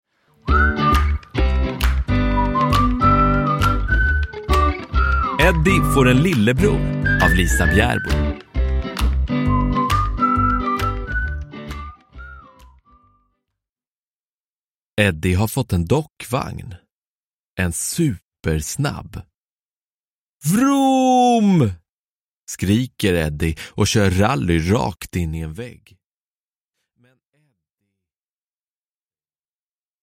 Eddie får en lillebror – Ljudbok – Laddas ner